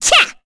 Cecilia-Vox_Attack1.wav